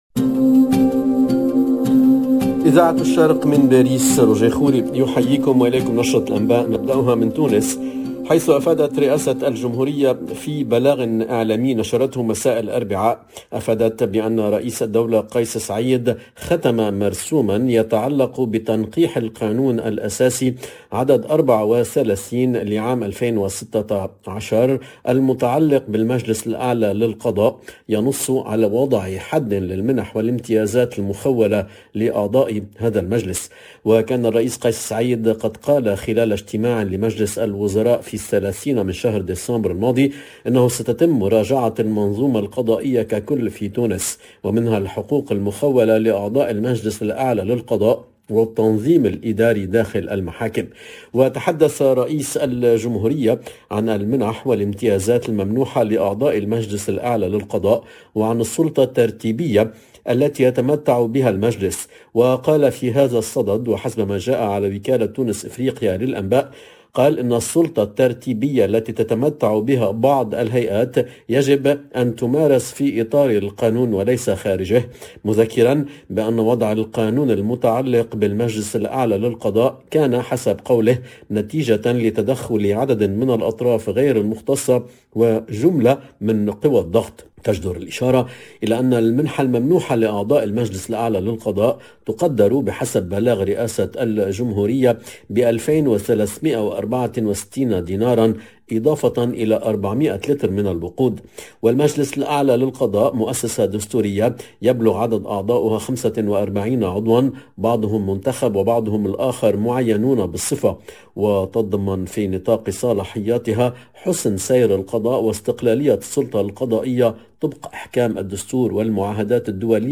LE JOURNAL DE MIDI 30 EN LANGUE ARABE DU 20/01/22